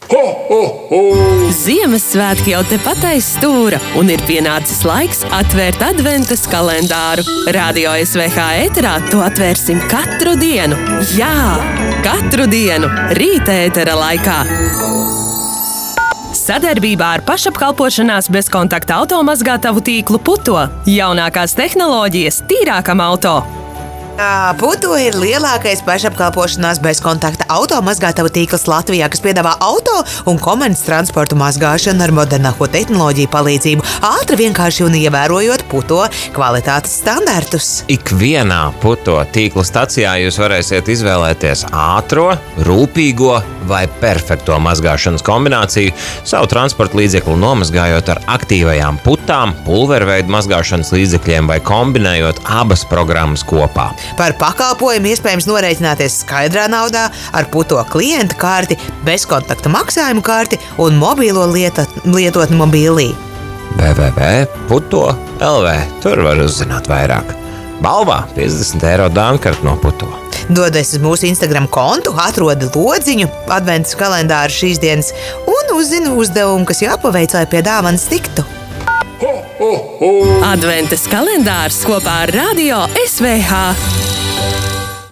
“Novadu balss” 6. decembra ziņu raidījuma ieraksts: